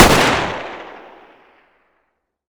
sako95_distance_fire1.wav